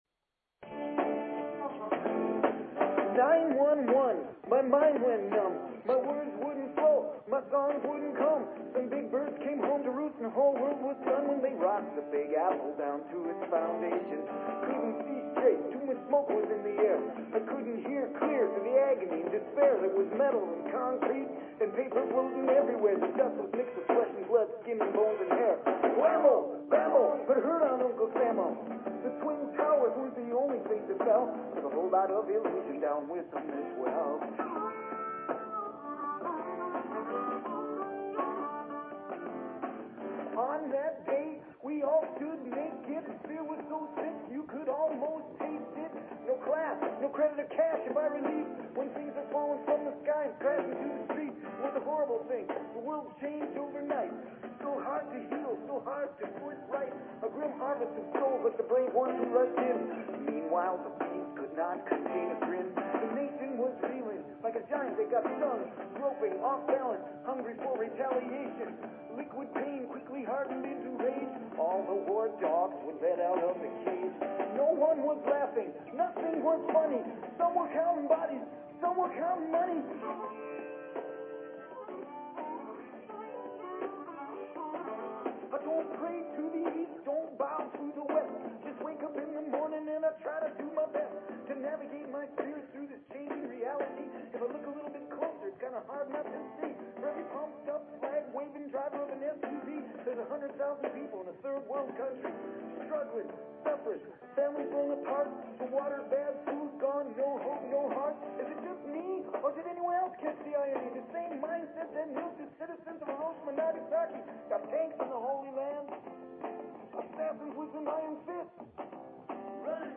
Talk Show Episode, Audio Podcast, Mission_Possible and Courtesy of BBS Radio on , show guests , about , categorized as
Show Headline Mission_Possible Show Sub Headline Courtesy of BBS Radio Show #3 (bad audio very low recording level) Fourth July show Mission Possible Please consider subscribing to this talk show.